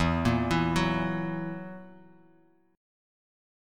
Esus2b5 Chord
Listen to Esus2b5 strummed